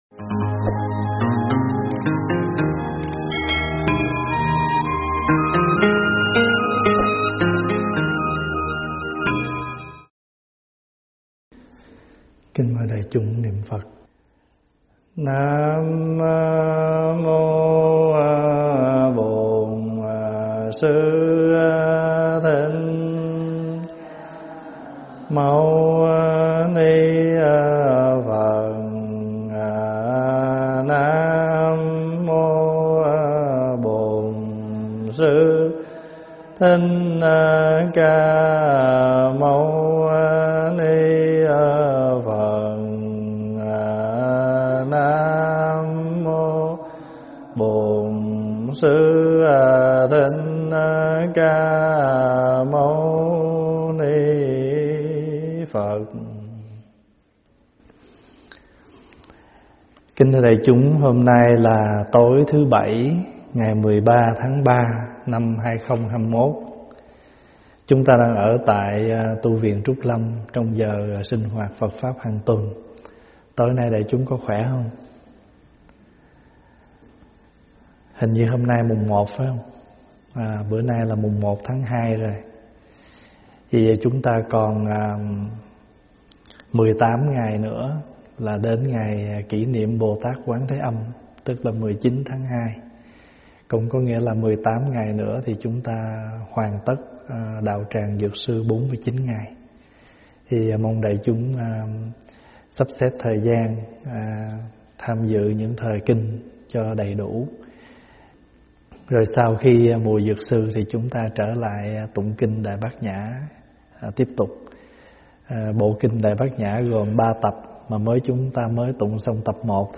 Thuyết pháp Báo Ân Rộng Lớn 27